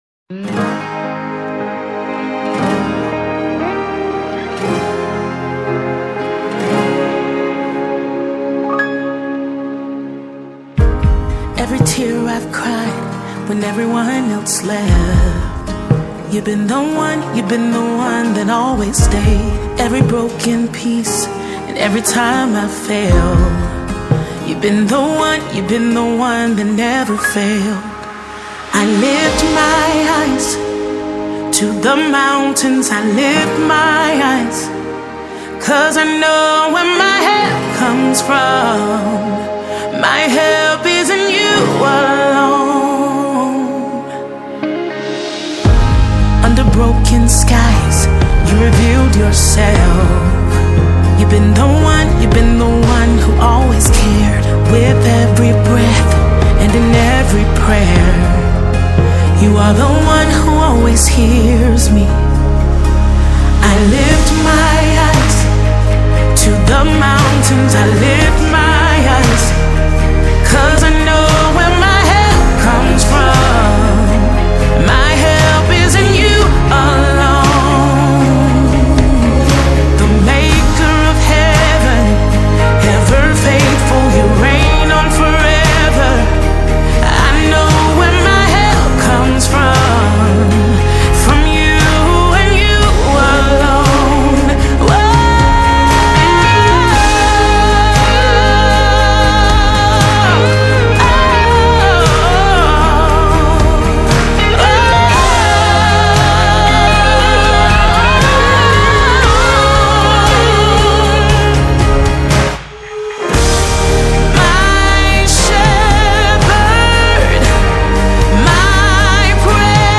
International Gospel Songs